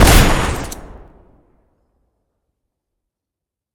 shoot2.ogg